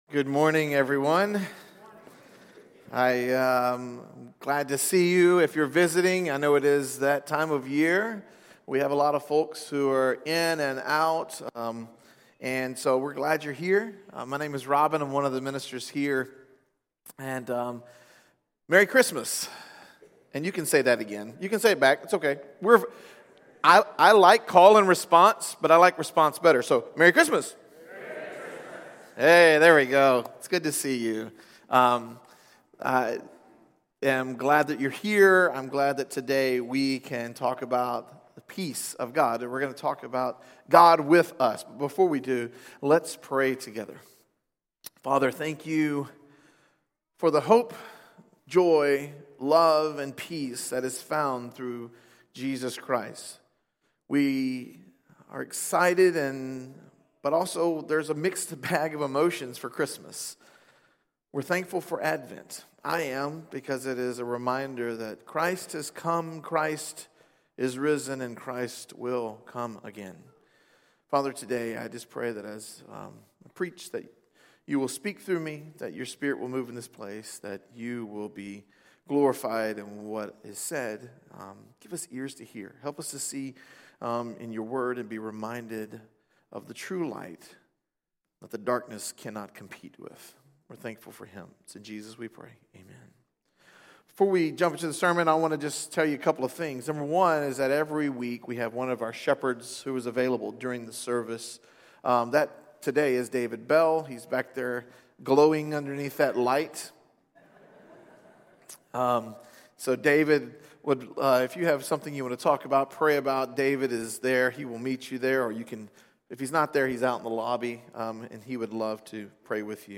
In this final Advent message, we celebrate Christmas as the moment God became flesh in Jesus Christ, offering light, salvation, and hope to the world. As Immanuel—God with us—He calls us to embrace His presence and reflect His light until His return.